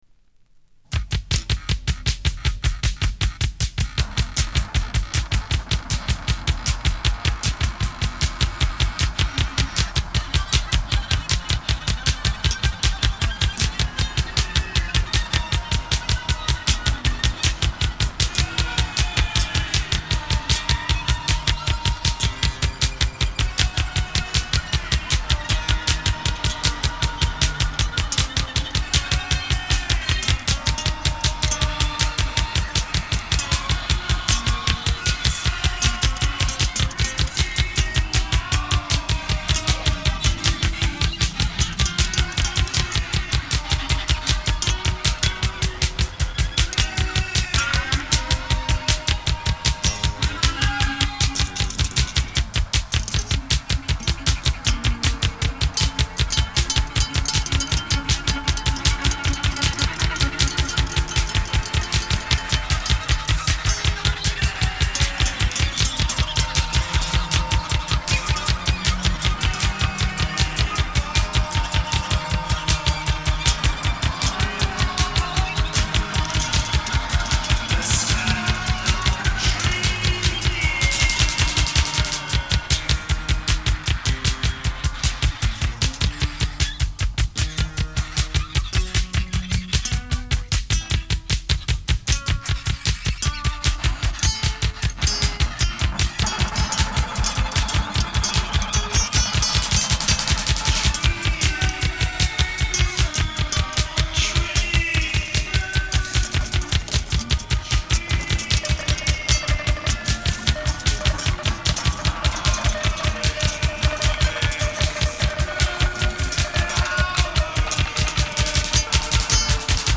Like many post-punk bands
guitar
Bass
Drums